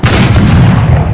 fireenemy3.ogg